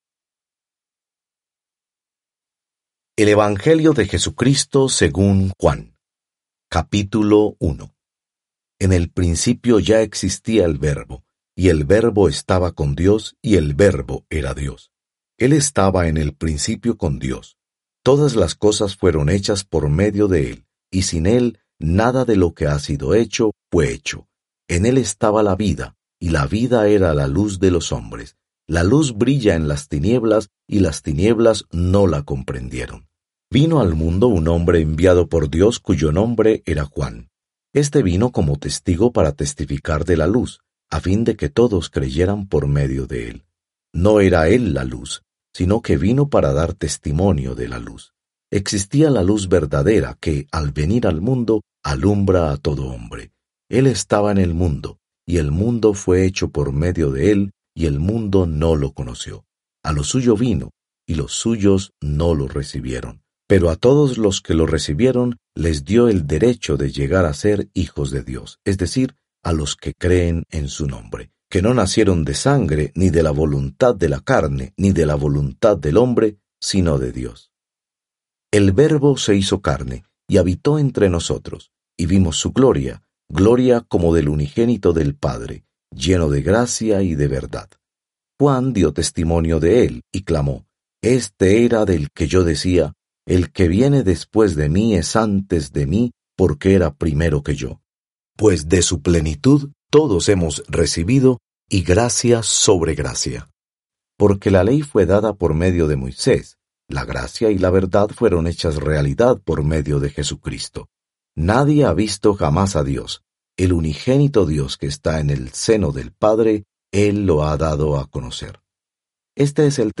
spanish-biblia-nbla-33219-genesis-1.mp3
• Word for word narration
• Voice only Bible reading